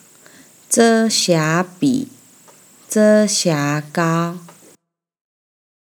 ヂェ゛ァ シァ ビー/ヂェ゛ァ シァ ガオ
zhē xiá bǐ zhē xiá gāo